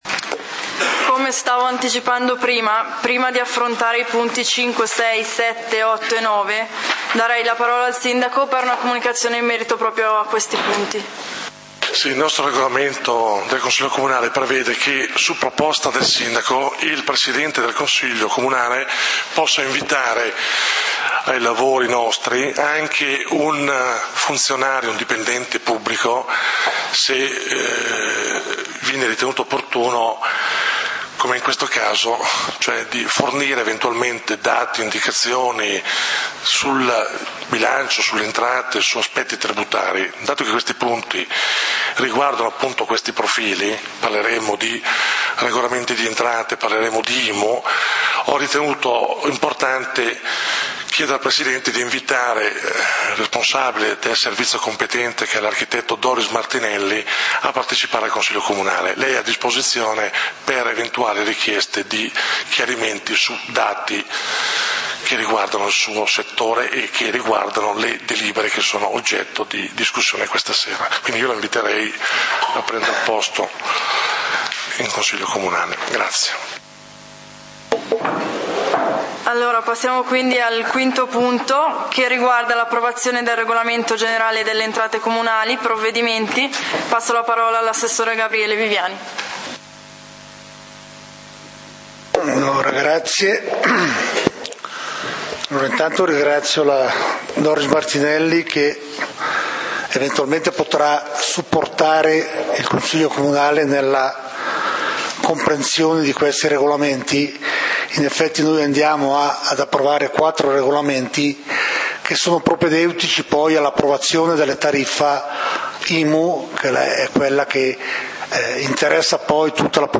Punti del consiglio comunale di Valdidentro del 30 Ottobre 2012